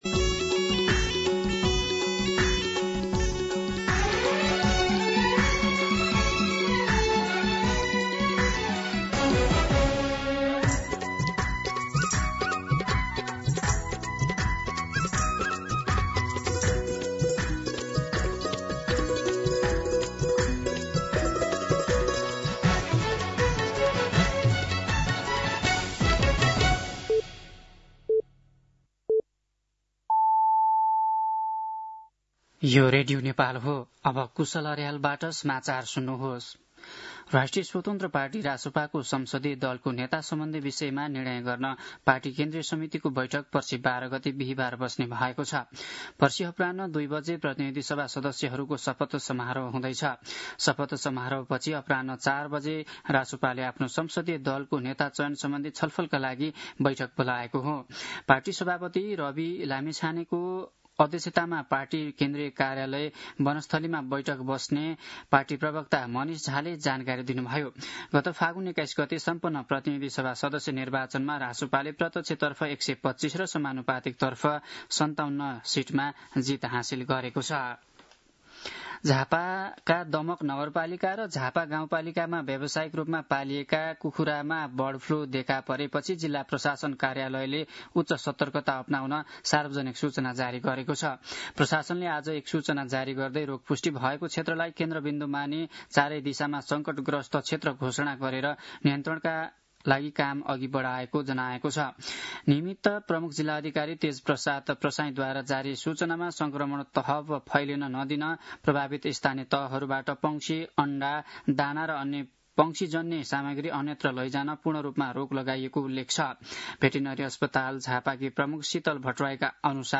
दिउँसो ४ बजेको नेपाली समाचार : १० चैत , २०८२